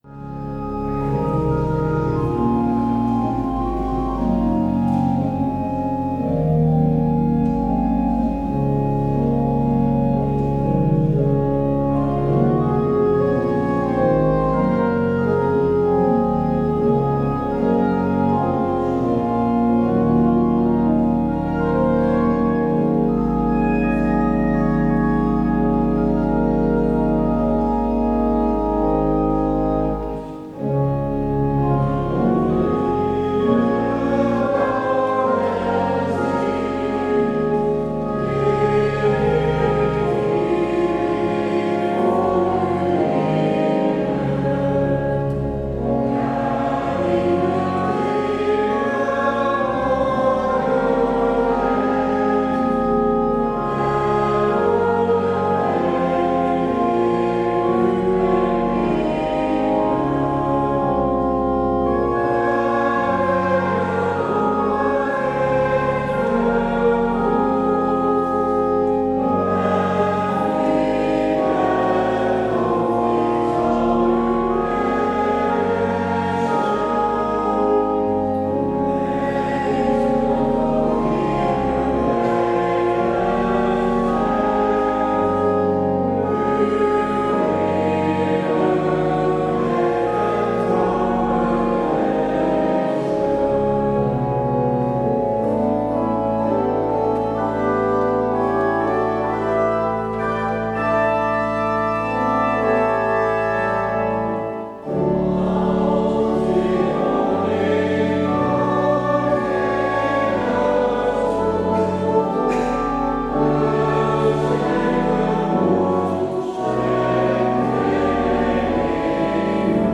Daarover gaat het in deze middagpauzedienst.